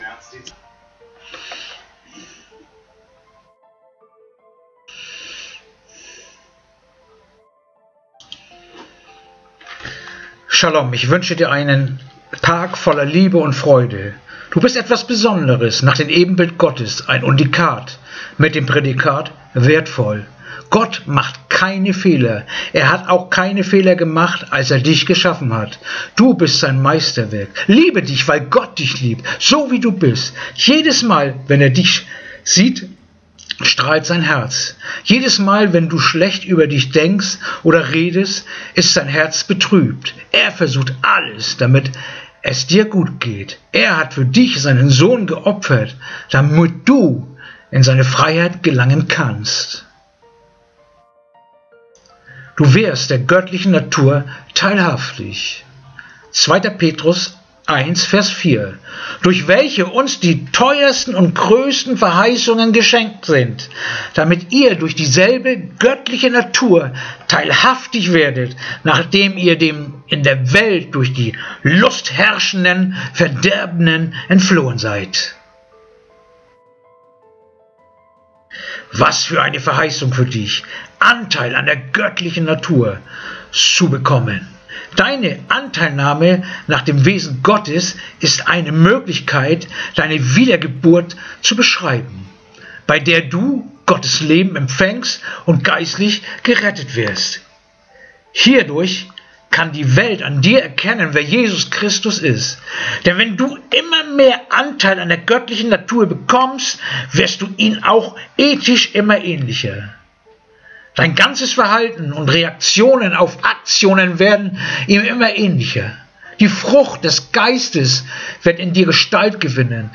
Andacht-vom-01-April-2.-Petrus-1-4.mp3